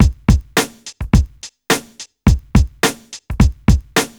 RB106BEAT2-L.wav